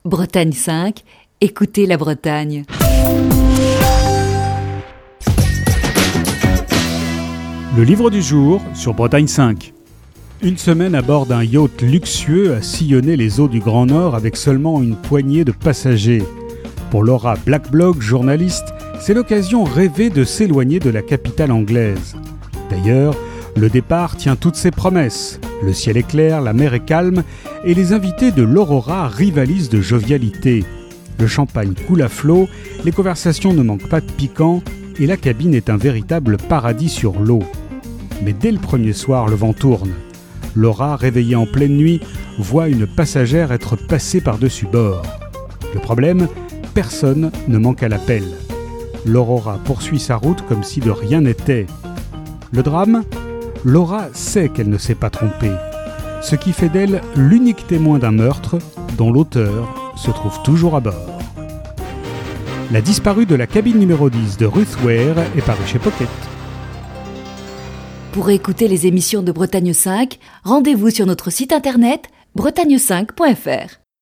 Fil d'Ariane Accueil Les podcasts La disparue de la cabine n° 10 - Ruth Ware La disparue de la cabine n° 10 - Ruth Ware Chronique du 31 janvier 2020.